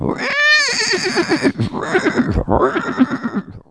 HORSE 1.WAV